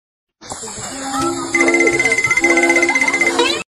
goofy ahh goofy sounds meme mp3 Meme Sound Effect
goofy ahh goofy sounds meme mp3.mp3